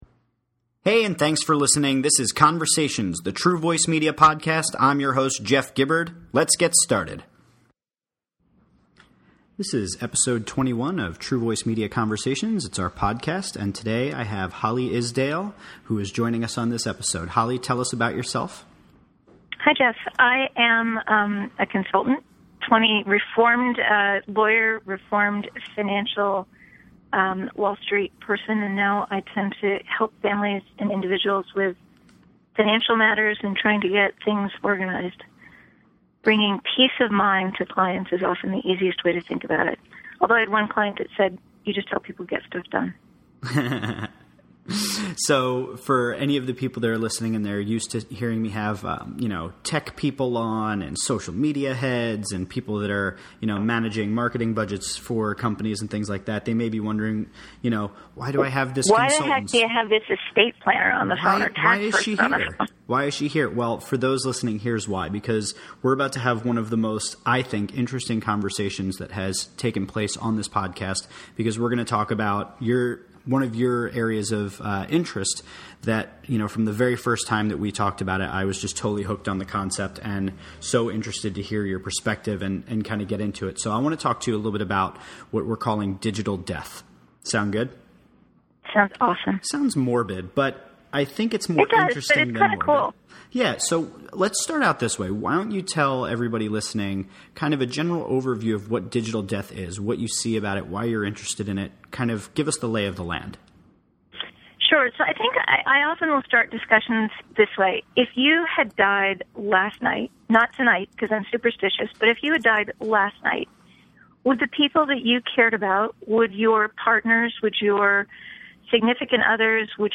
A cheery and uplifting chat about Digital Death